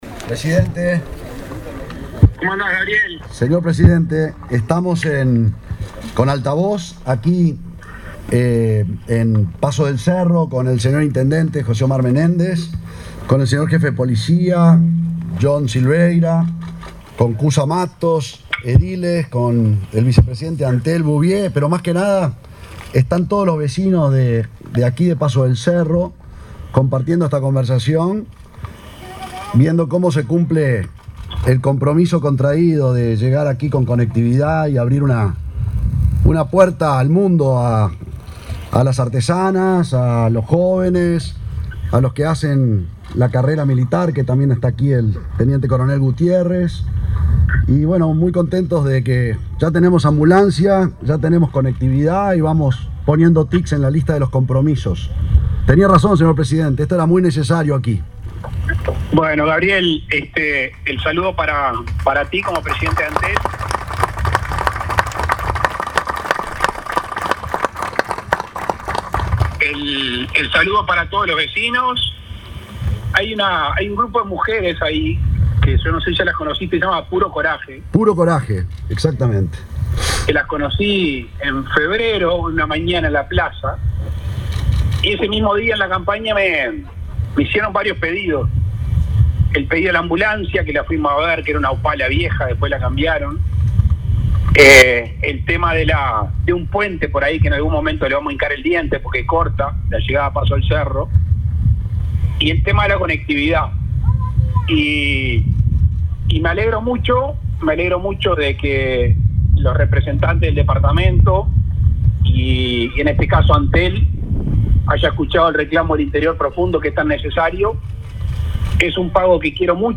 Lacalle Pou participó vía telefónica en inauguración de nueva base LTE de Antel en Paso del Cerro, Tacuarembó
“Esta es la llamada más importante en mucho tiempo”, afirmó el presidente, Luis Lacalle Pou, ante los pobladores de Paso del Cerro, Tacuarembó, durante el contacto telefónico que el titular de Antel, Gabriel Gurméndez, realizó durante la inauguración de la nueva radiobase LTE en esa localidad, ubicada a 40 kilómetros de la capital departamental. Entre otras mejoras, la escuela n.° 36 optimizará su conexión a internet.